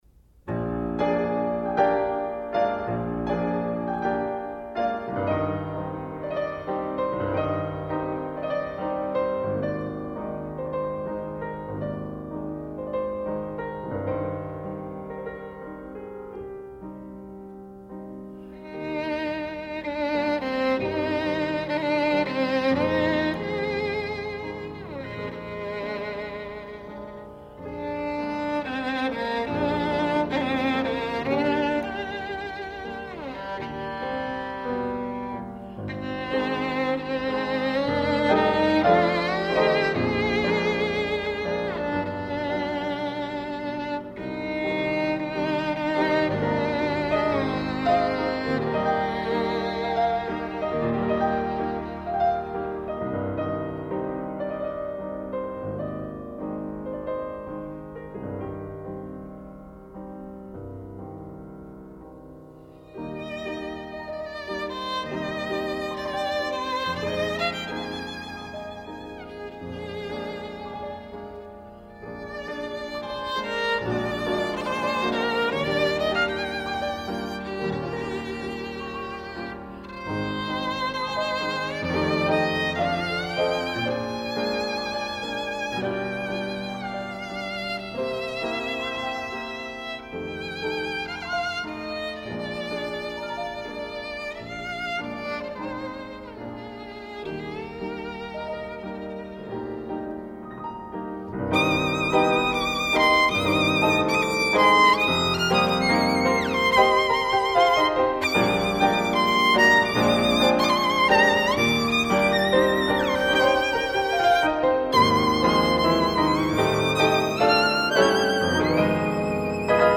琴的音色和空间感无可匹敌，琴声的起伏更具感染力，
而伴奏的钢琴与小提琴之间的分隔度也更为清晰。